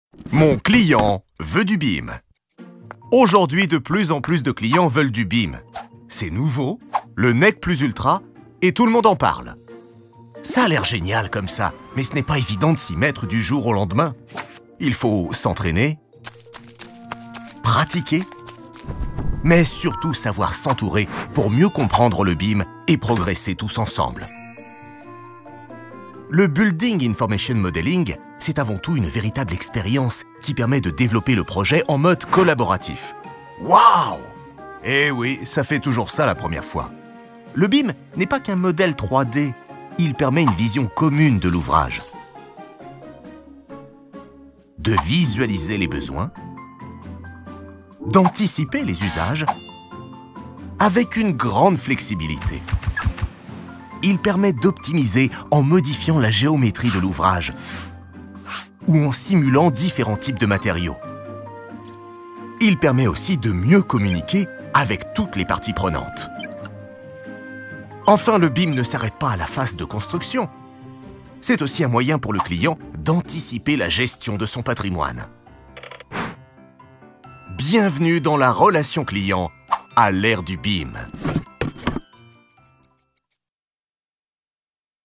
Mon expérience de voix-off sur BIM : un ton amusant, dynamique, drôle et explicatif
Voix sympa et amusante.
Film corporate pour le BIM de Bouygues.
Avec une hauteur de voix médium grave, j’ai réussi à apporter une tonalité amusante, dynamique, drôle, explicative et sympathique à la publicité.
Pour mettre en avant le BIM, j’ai utilisé ma voix pour communiquer de manière efficace et attrayante les avantages et les caractéristiques du building information modeling. J’ai su donner à la publicité un ton drôle et sympathique, tout en restant explicatif et dynamique.